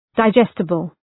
Προφορά
{dı’dʒestəbəl}